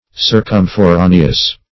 Circumforaneous \Cir`cum*fo*ra"ne*ous\, a.